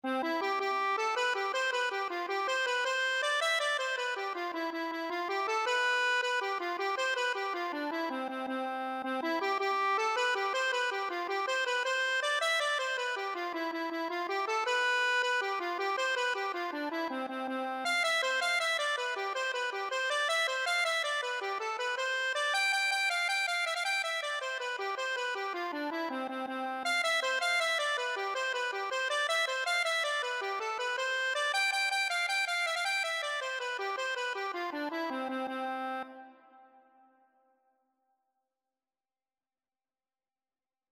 6/8 (View more 6/8 Music)
Accordion  (View more Easy Accordion Music)
Traditional (View more Traditional Accordion Music)